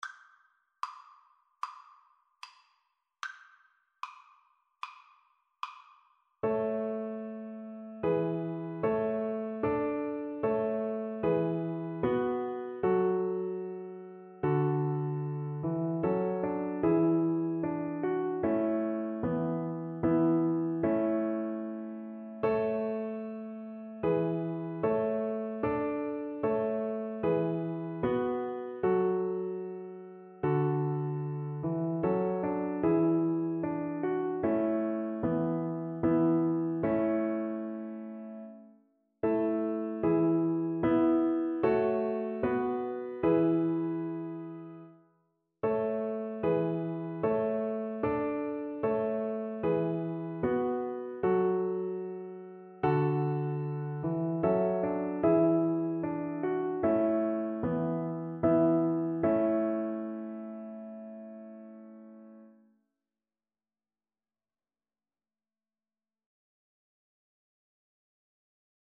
Christmas Christmas Violin Sheet Music Es ist ein Ros' entsprungen
Violin
A major (Sounding Pitch) (View more A major Music for Violin )
4/4 (View more 4/4 Music)
Moderato
Traditional (View more Traditional Violin Music)